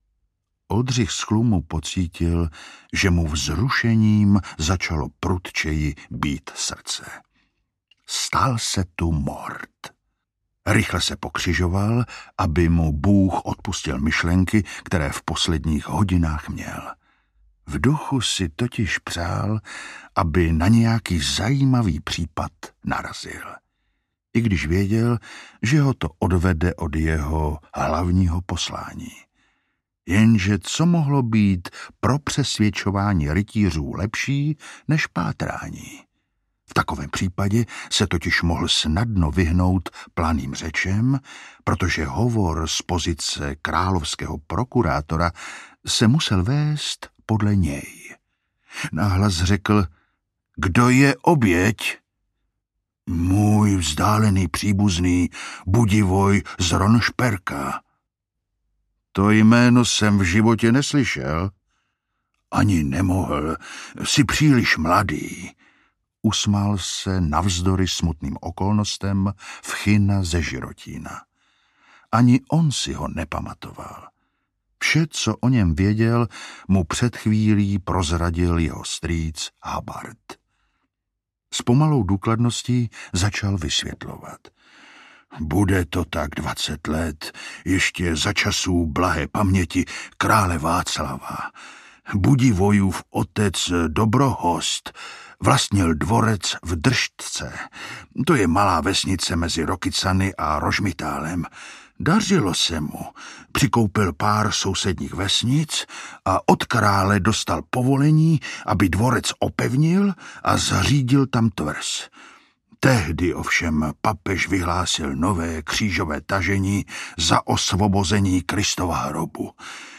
Černá lilie audiokniha
Ukázka z knihy
Vyrobilo studio Soundguru.